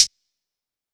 Closed Hats
MB Hihat (2).wav